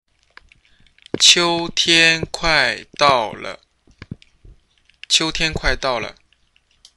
qiu1　 tian1　 kuai4　 dao4 　le
発音：ダウンロード